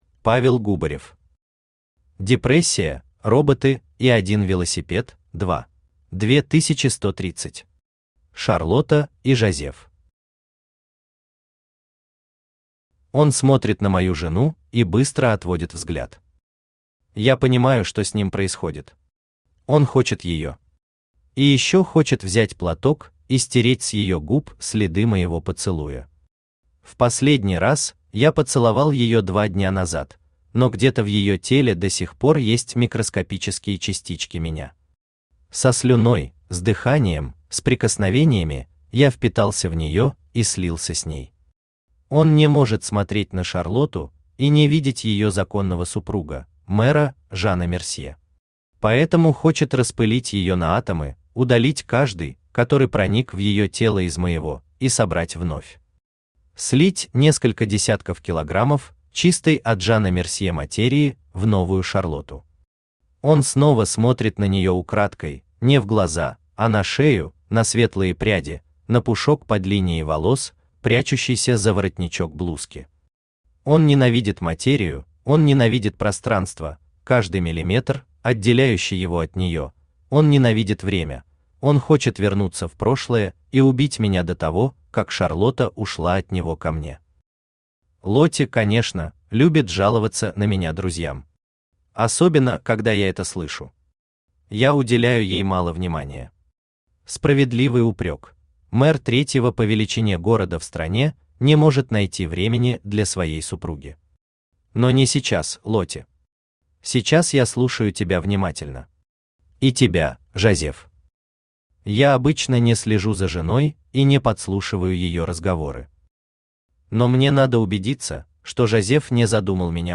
Aудиокнига Депрессия, роботы и один велосипед – 2 Автор Павел Николаевич Губарев Читает аудиокнигу Авточтец ЛитРес.